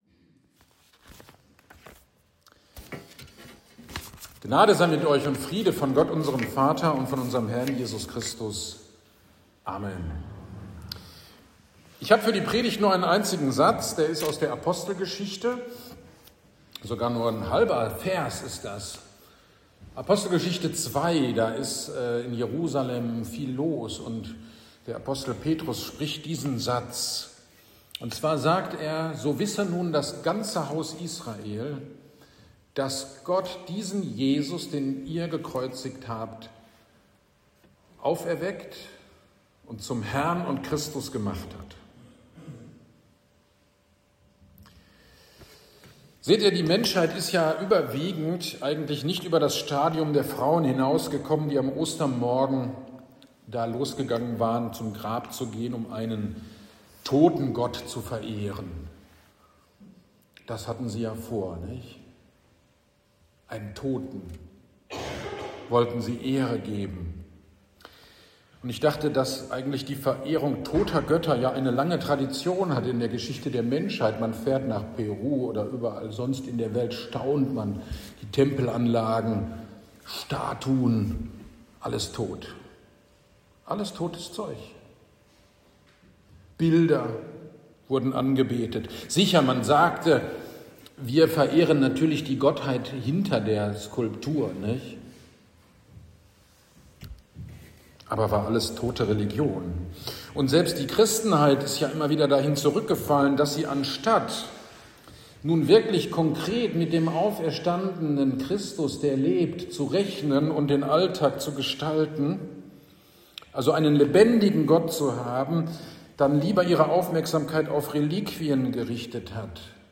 GD am 05.04.26 (Ostern) Predigt zu Apostelgeschichte 2, 42a